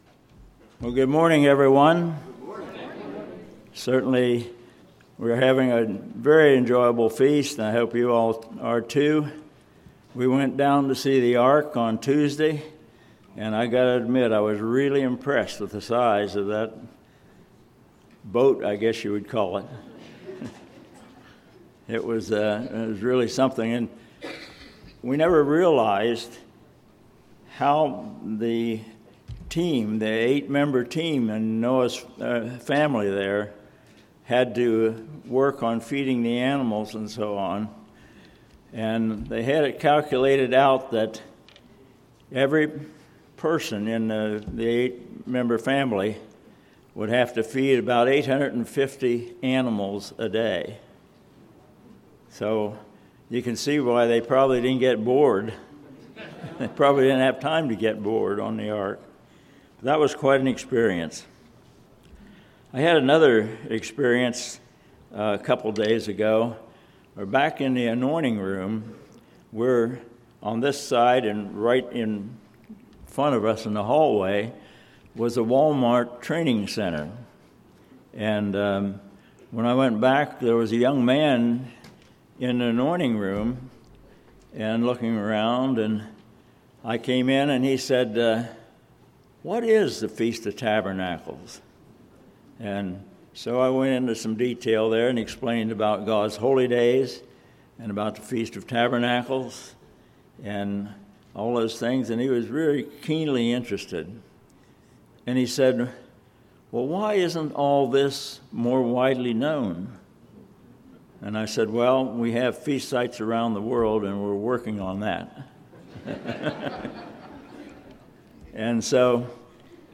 This sermon was given at the Cincinnati, Ohio 2016 Feast site.